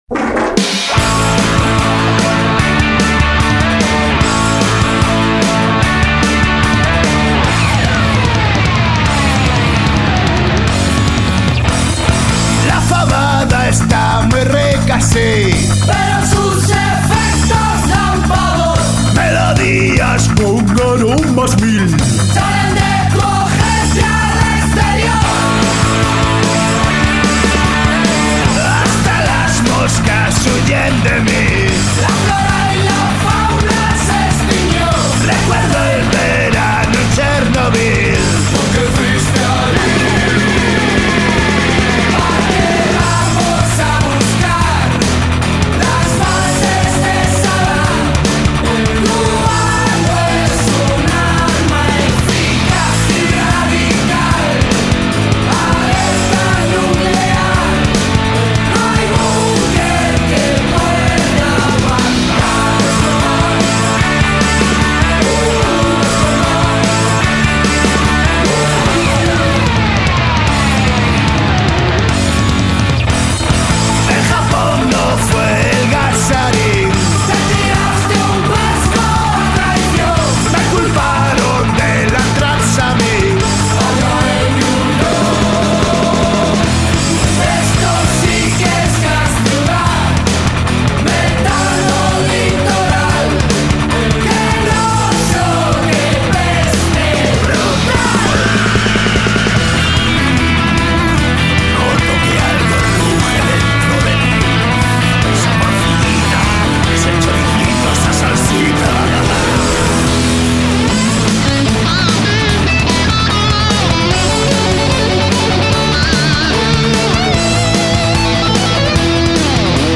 a covers band